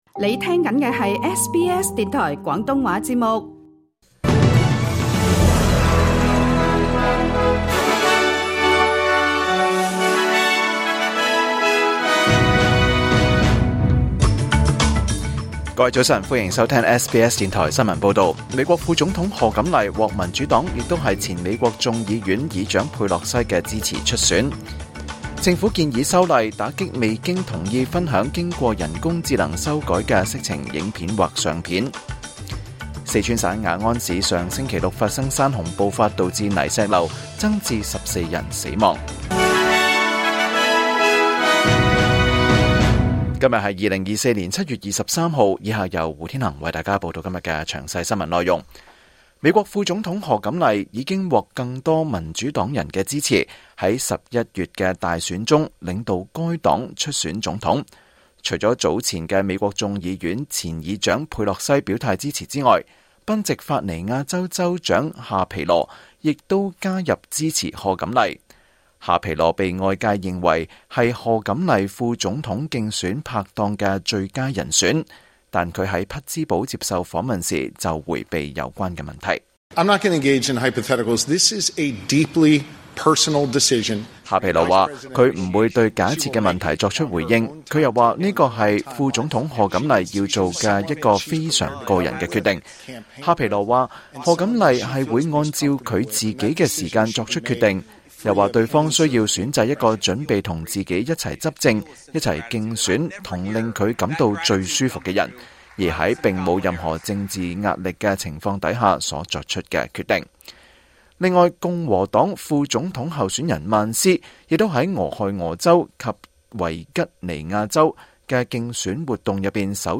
2024年7月23日SBS廣東話節目詳盡早晨新聞報道。
SBS廣東話新聞報道